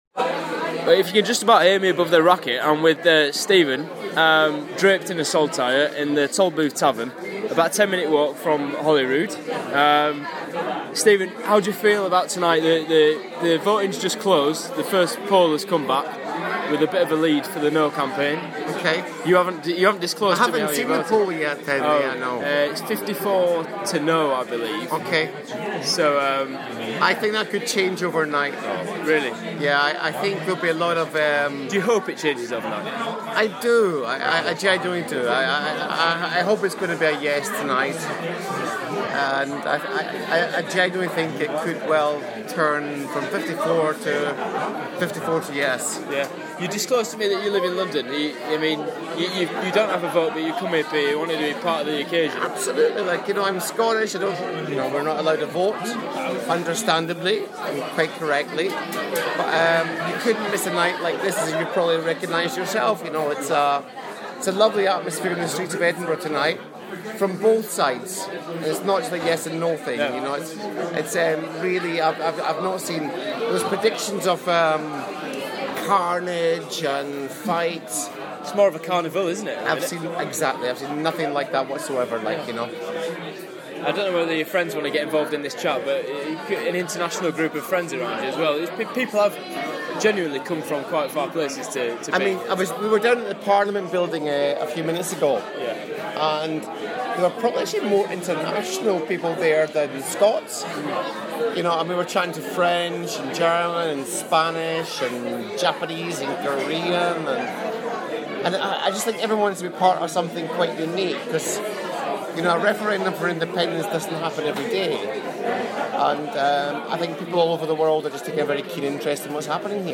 in a boozer on Canongate